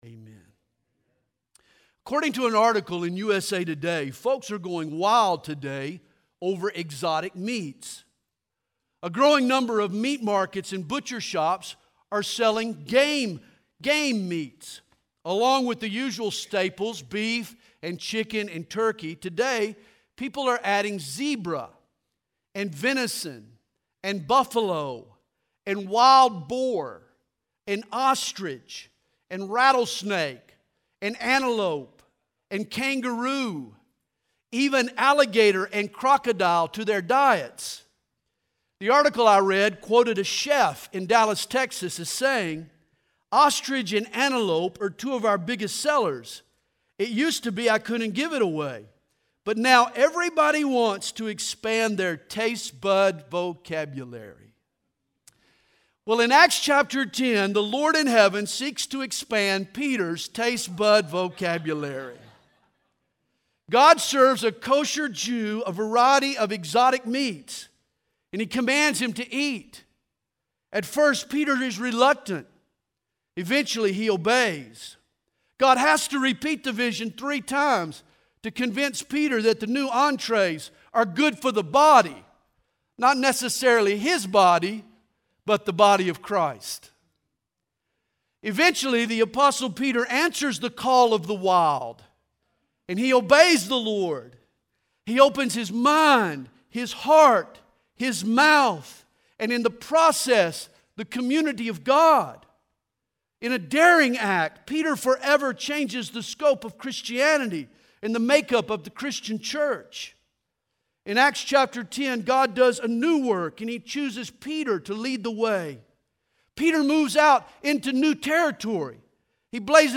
2019 DSPC Conference: Pastors & Leaders Date